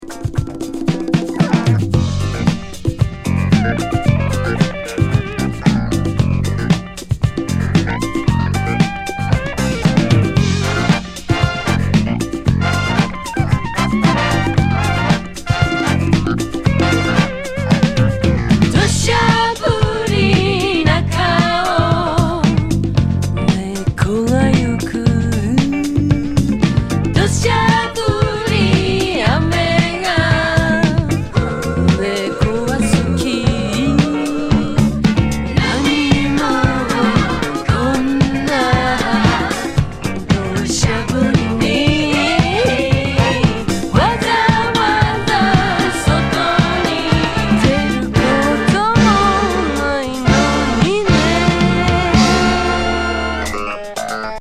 和ファンク